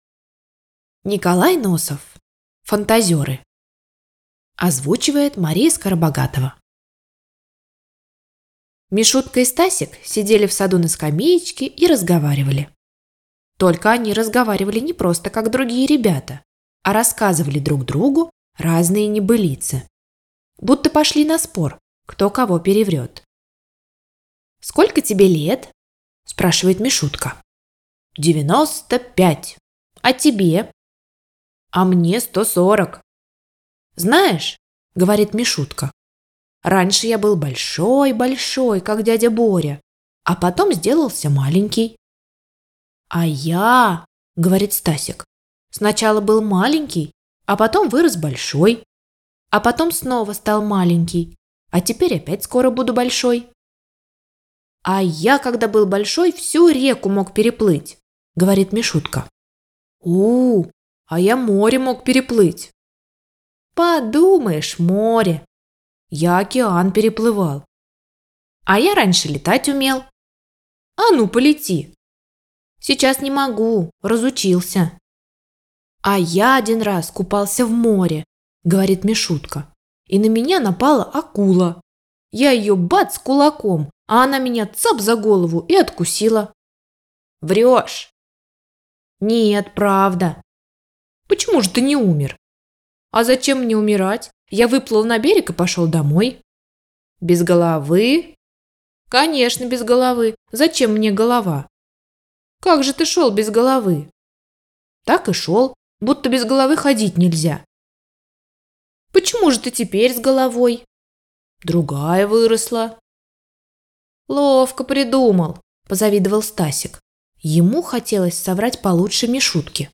"Фантазёры" Н. Носов - Аудиосказки